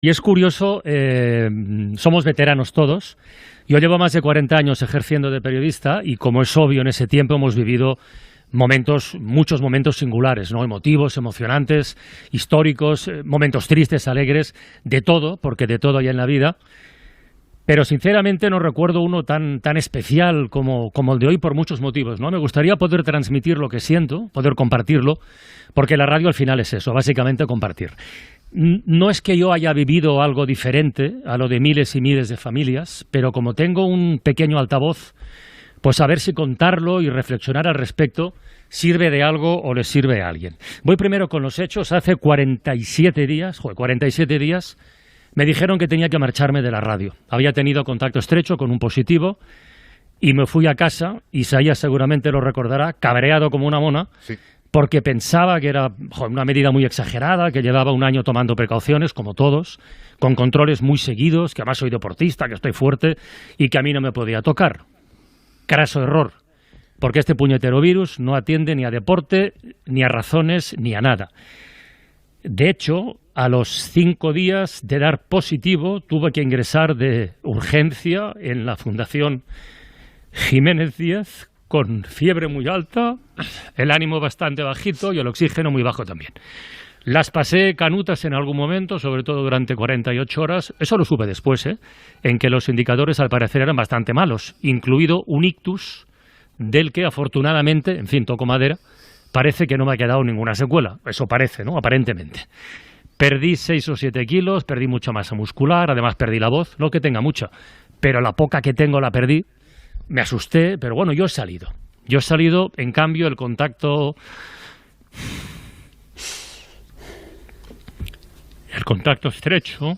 Explica en primera persona com ha viscut el seu ingrés hospitalari, l'estima de la seva família, dels companys de la ràio i l'audiència
Entreteniment